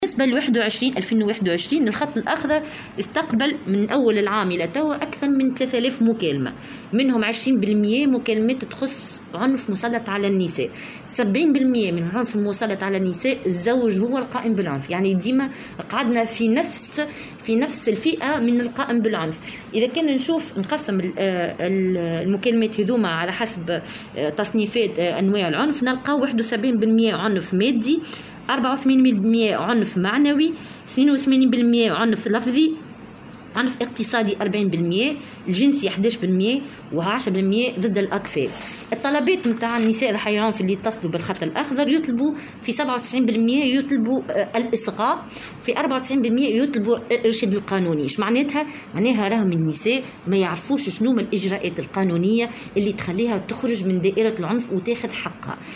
وأضافت في تصريح لمراسل "الجوهرة أف أم" اليوم على هامش ورشة حول تركيز المنظومة الوطنية لتوجيه النساء ضحايا العنف، أن 71 بالمائة من حالات العنف المسجلة هي عنف مادي و11 بالمائة عنف جنسي و10 بالمائة عنف ضدّ الأطفال.